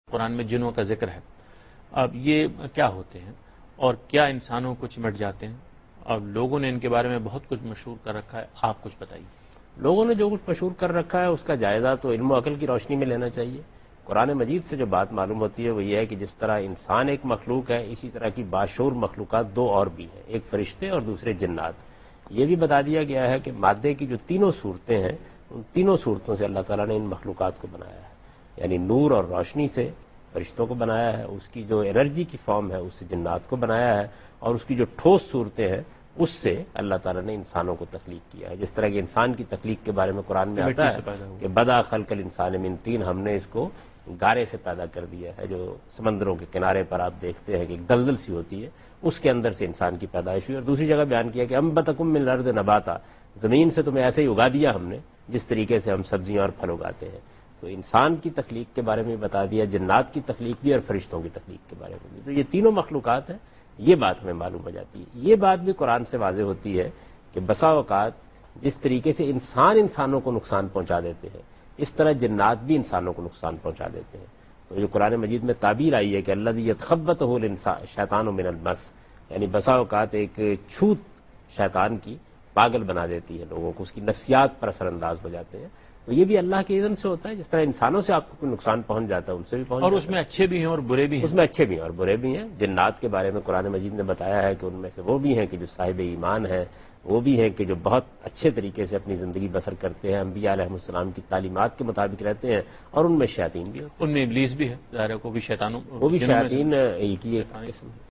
Category: TV Programs / Dunya News / Deen-o-Daanish /
Javed Ahmad Ghamidi answers a question regarding "The Jinn: Myth and Reality" in Dunya Tv's program Deen o Daanish.